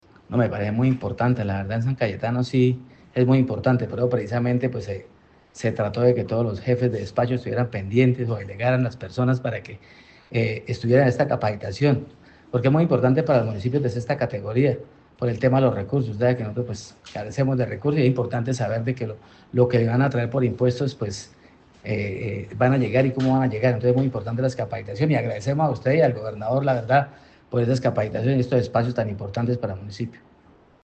Audio-de-Wilson-Perez-alcalde-de-San-Cayetano.mp3